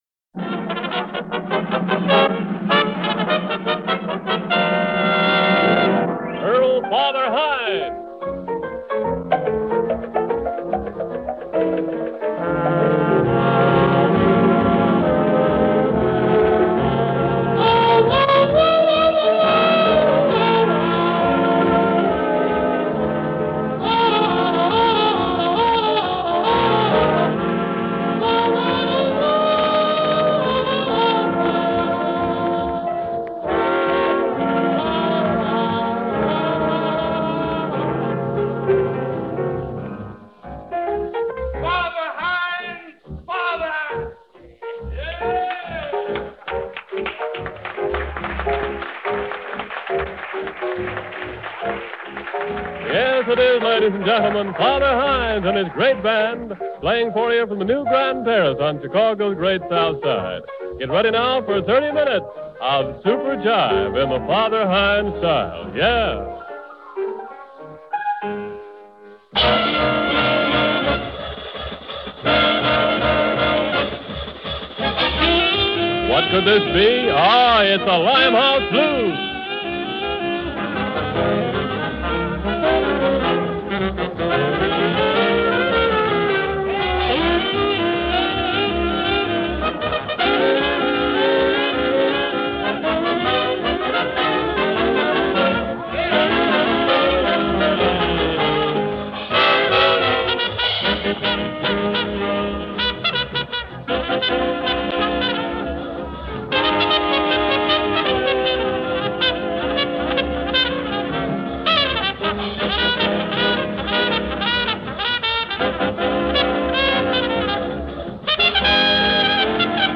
at a club date in Chicago
revolutionized the Stride style of piano playing.